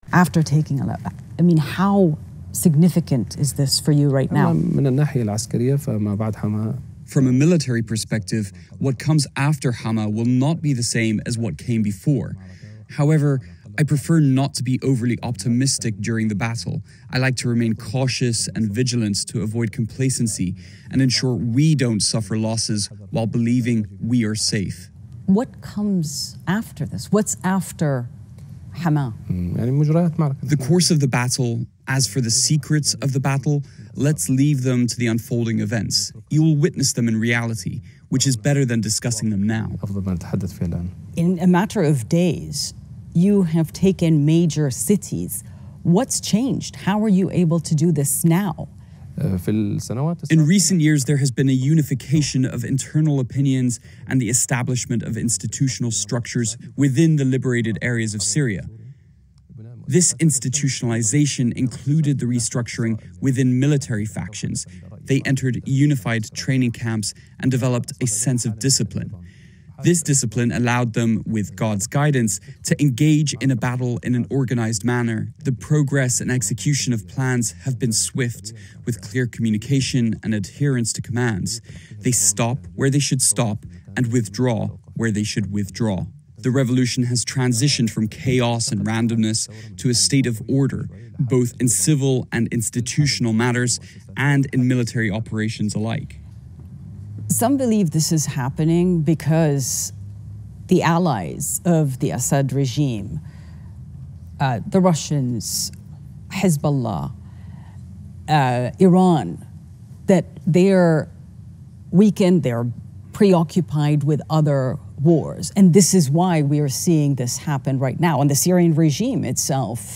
مصاحبه-سی-ان-ان-با-جولانی-رهبر-تحریرالشام.mp3